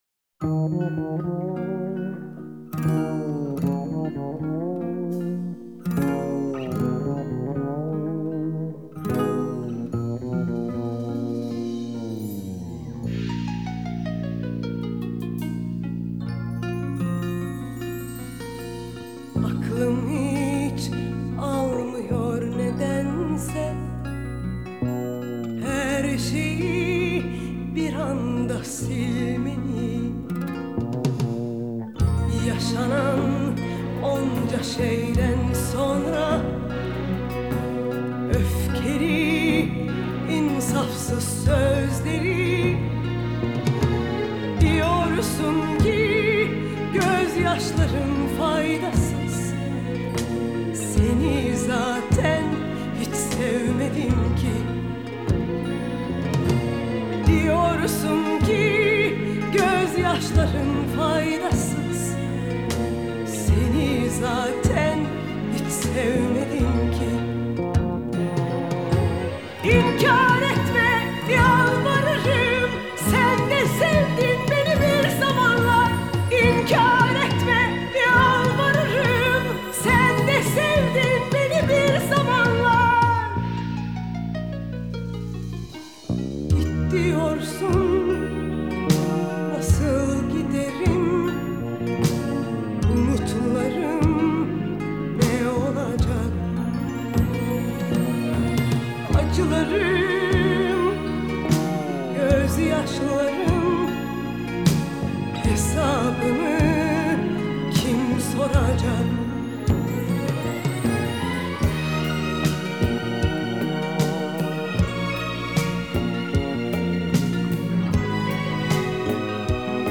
ژانر: پاپ و رپ
اهنگ ترکی خواننده زن جدید